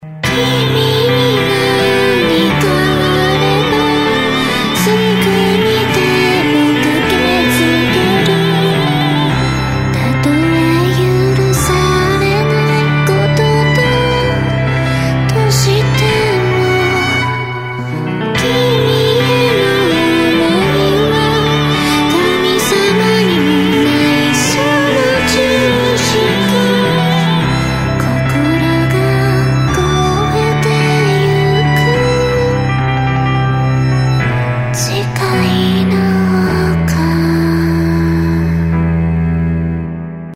★録り下ろしキャラクターソング５曲＋各カラオケ